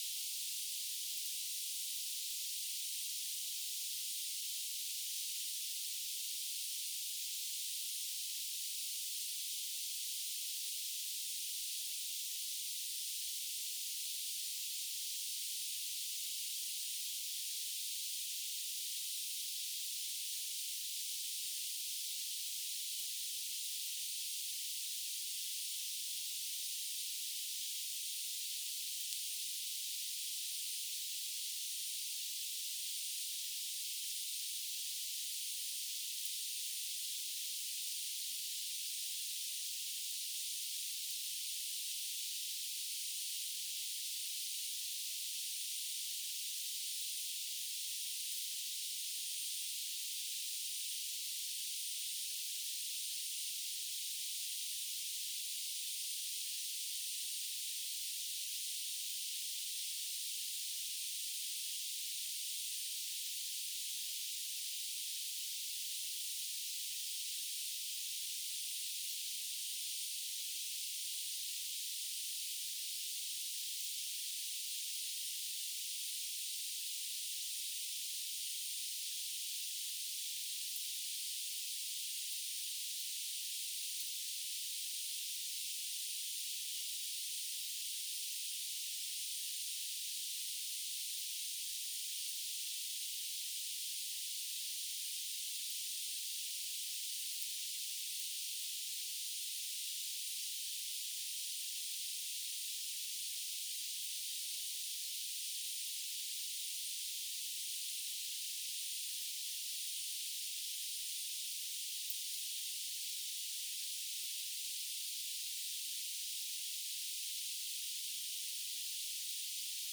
Demonstration soundscapes
61564 | biophony 61562 | biophony 61561 | biophony 61563 | anthropophony